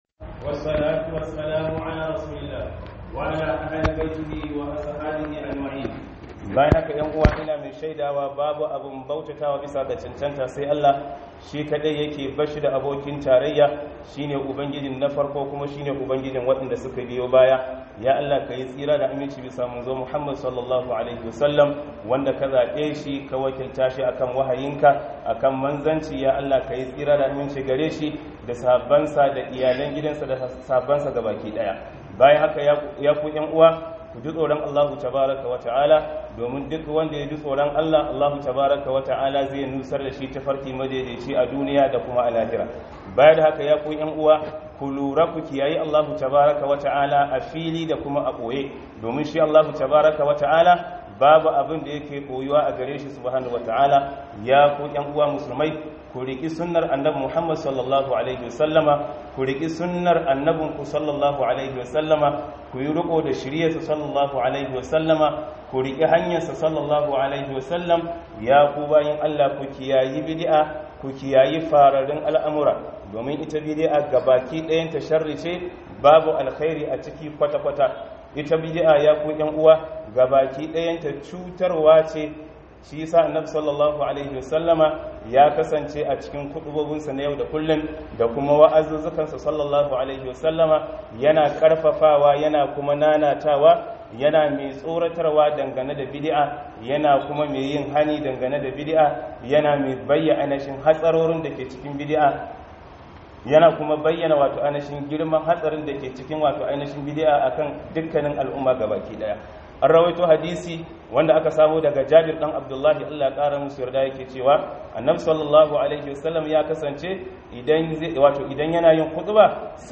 Khuduba - Riko da Sunna